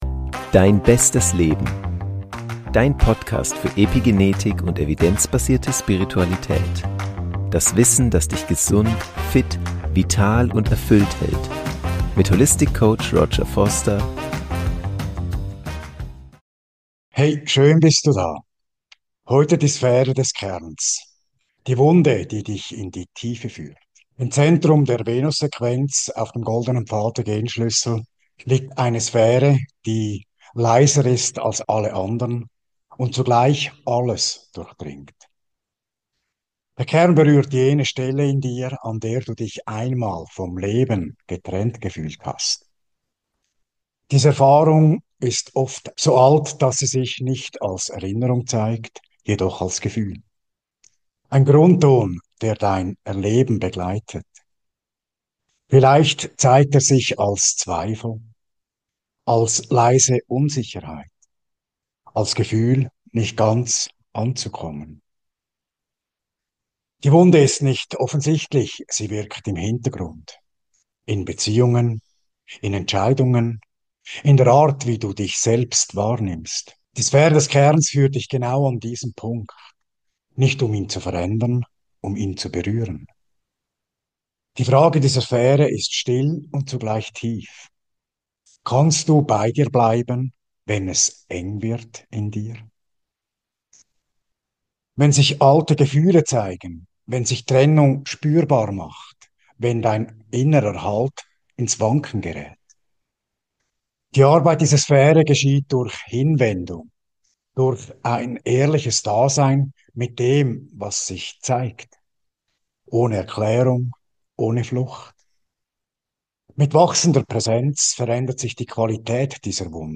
Diese kontemplative Meditationsreise ist der tiefste Teil der Venus-Sequenz im Goldenen Pfad der Gene Keys. Ein Raum, in dem Du erfahren kannst, was geschieht, wenn Du Dich nicht verlässt.
Am besten mit Kopfhörern.